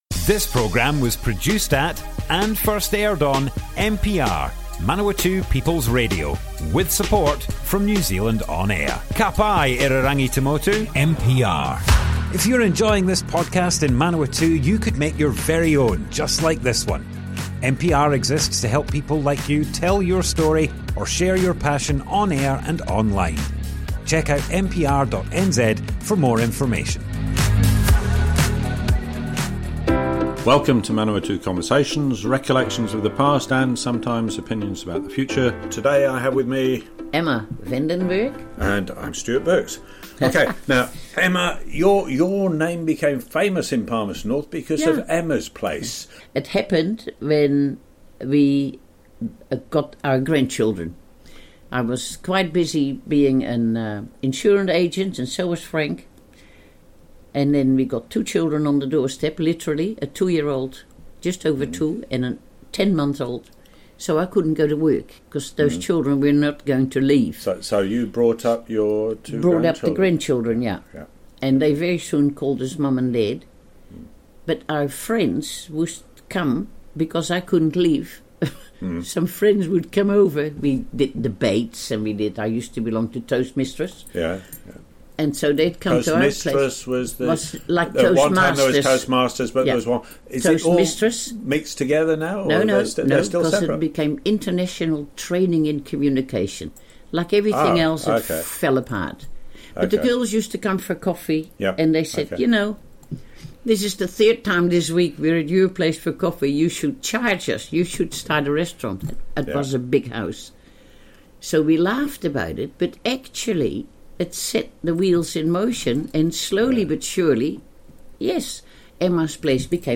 Manawatu Conversations More Info → Description Broadcast on Manawatu People's Radio, 19th August 2025.
oral history